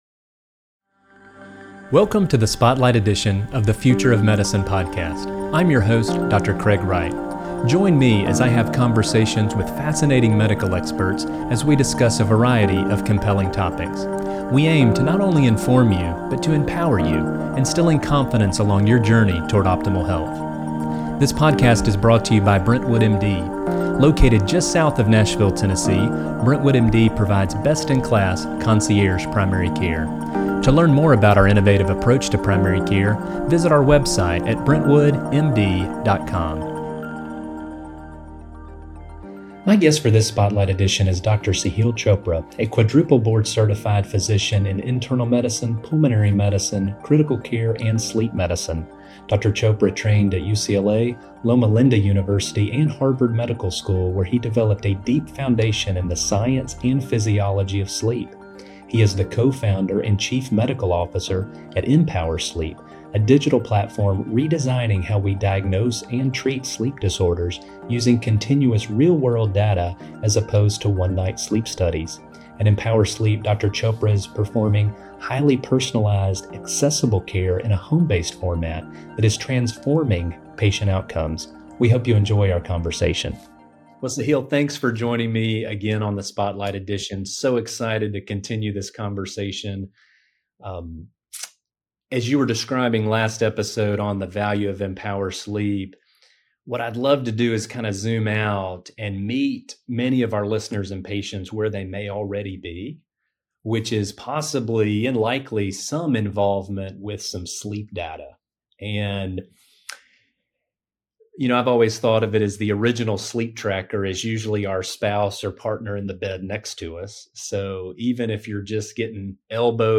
In our second discussion, I wanted to find out more about what each of us can do personally to improve our sleep, especially with the rise of wearable technology and its readily available sleep data.